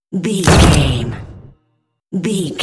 Dramatic hit drum metal
Sound Effects
Atonal
heavy
intense
dark
aggressive